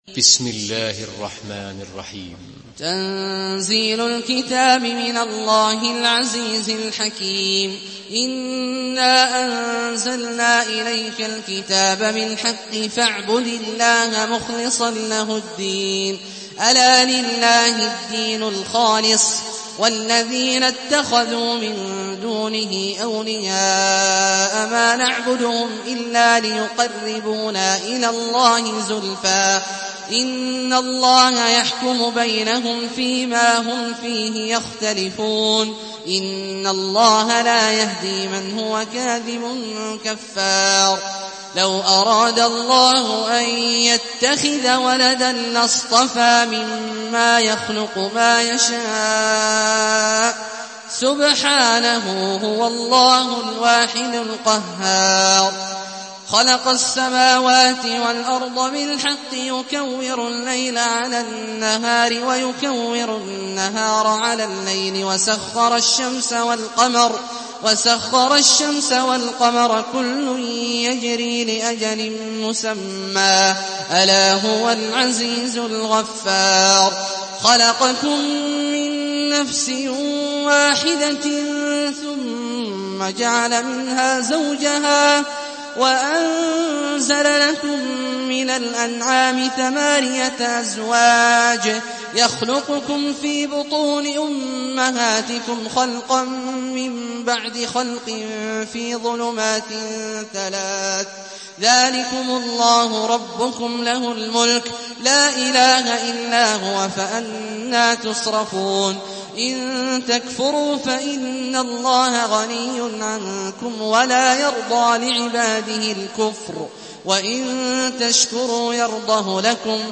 Surah Az-zumar MP3 in the Voice of Abdullah Al-Juhani in Hafs Narration
Murattal Hafs An Asim